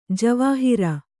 ♪ javāhira